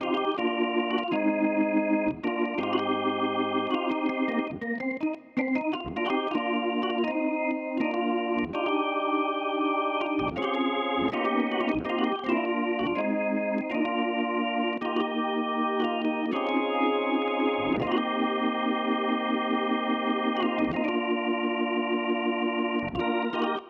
11 organ C.wav